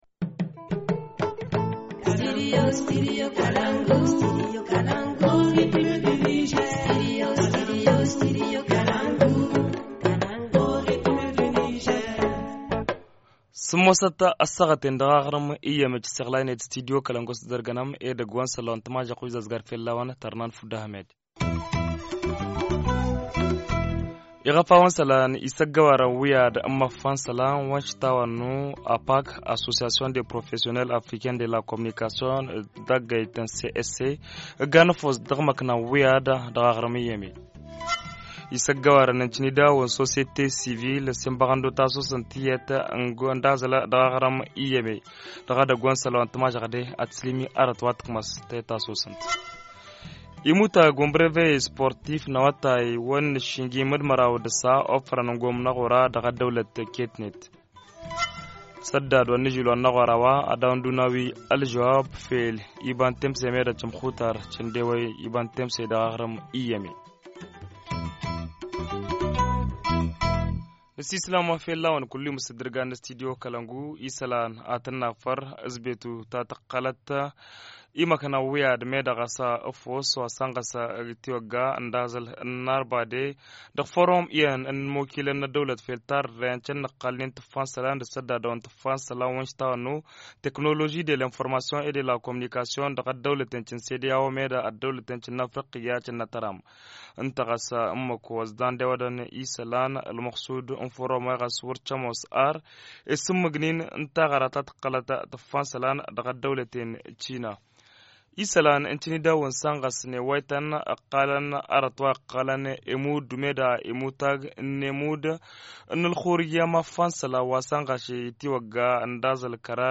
Journal du 4 Mai 2017 - Studio Kalangou - Au rythme du Niger